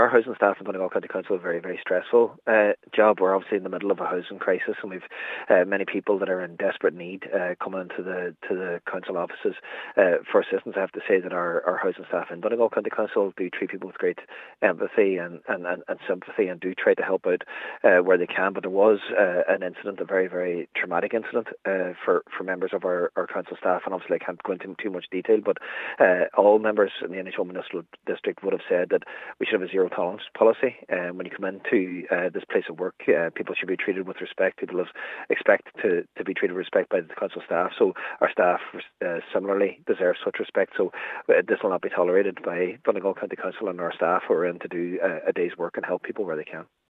Cathaoirleach of the Inishowen Municipal District, Councillor Jack Murray has condemned the incident: